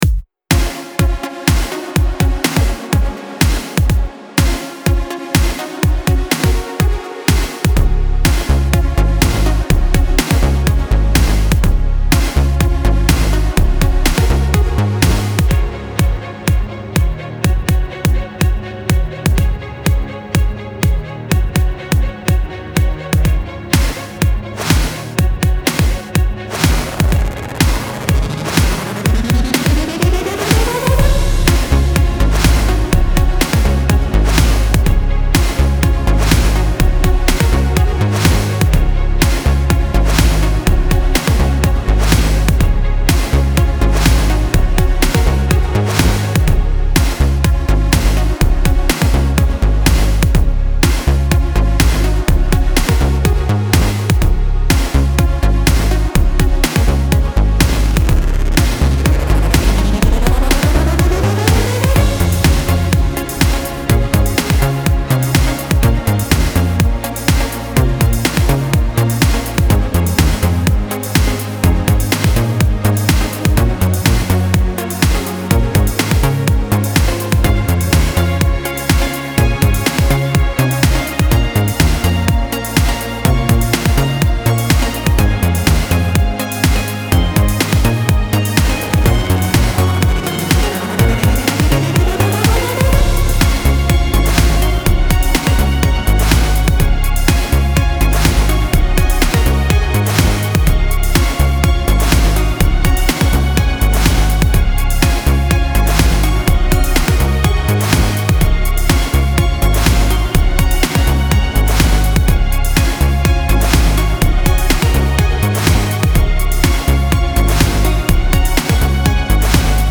Style Style EDM/Electronic
Mood Mood Epic, Uplifting
Featured Featured Bass, Drums, Strings +1 more
BPM BPM 124